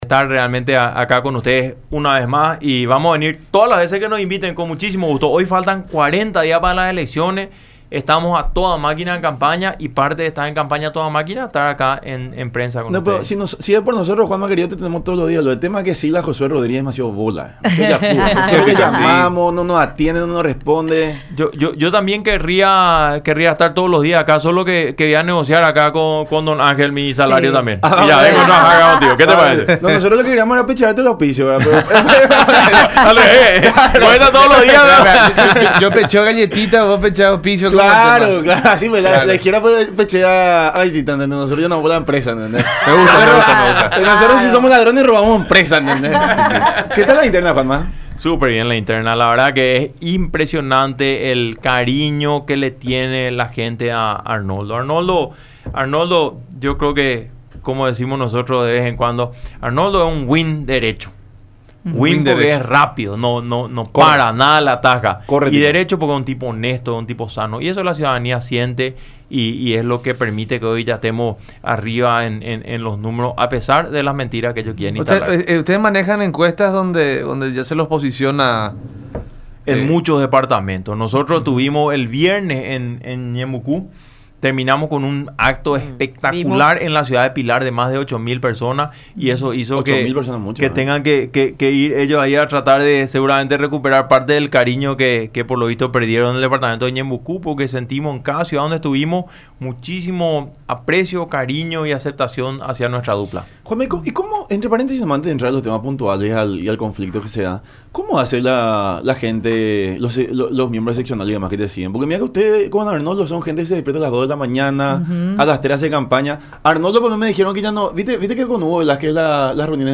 en su visita a los estudios de Unión TV y radio La Unión durante el programa La Unión Hace La Fuerza.